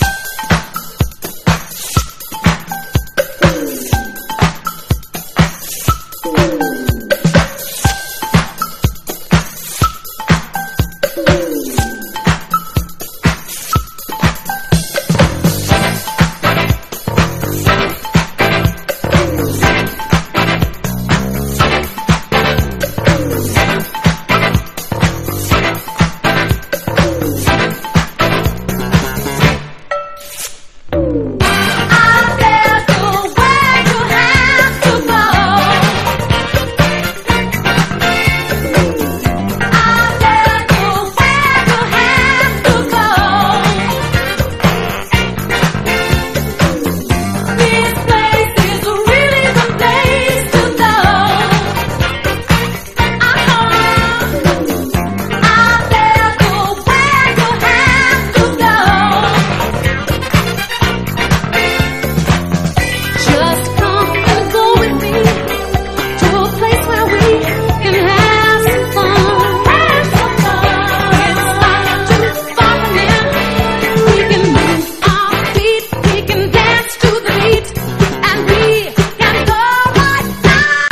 ライブラリー好きも必聴のファンキーで洒脱なラテン・ラウンジ！